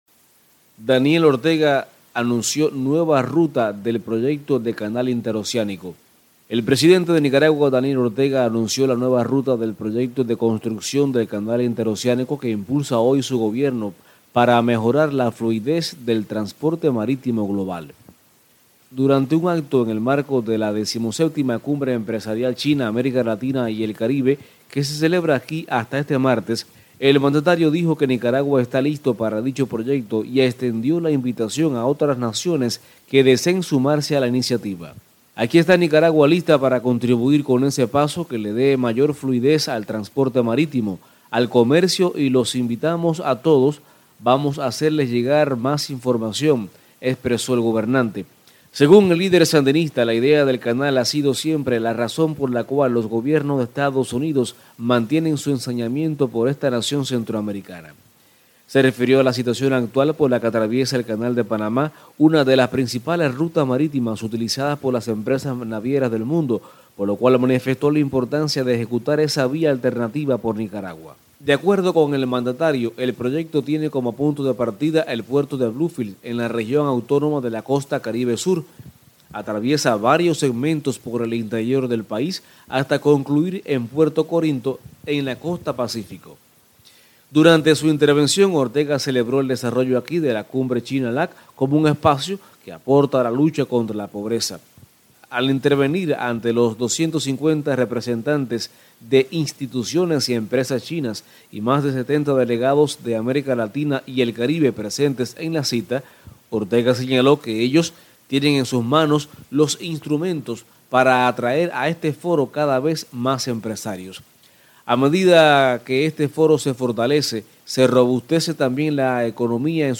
desde Managua